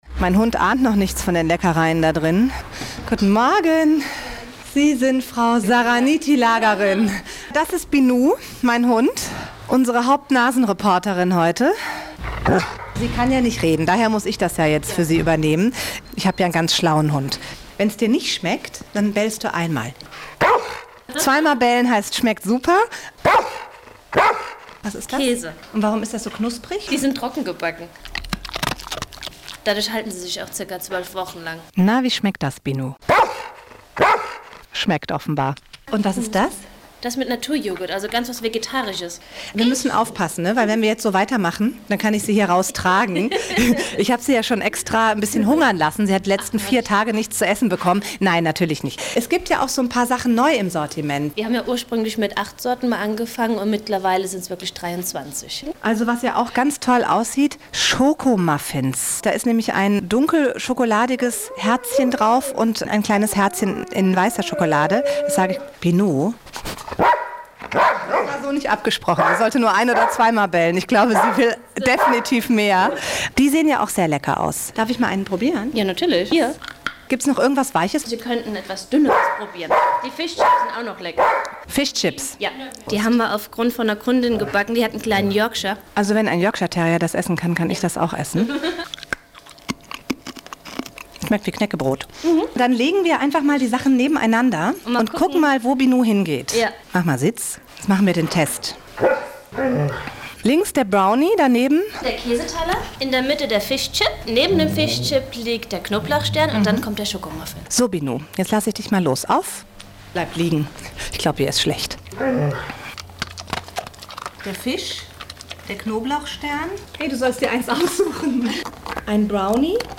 hr3-extra-Hundebäckerei-Reportage.mp3